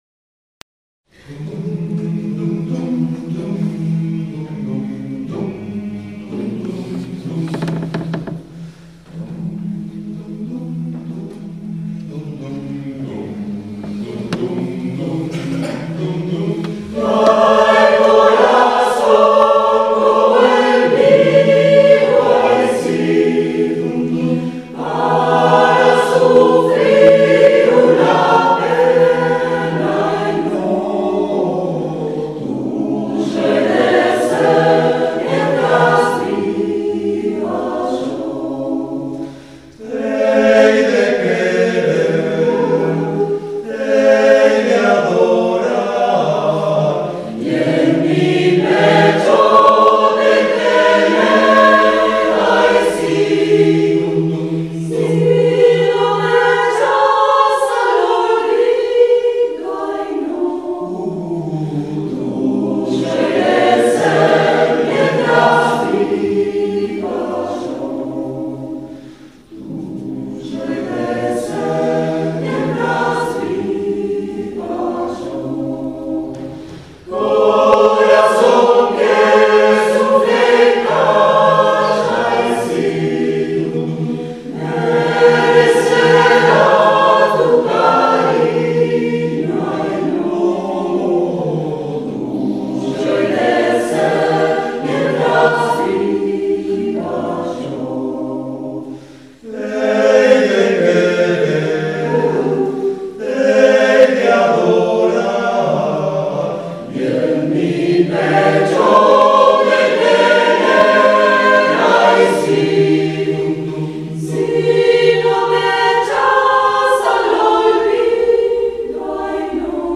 Vidala tradicional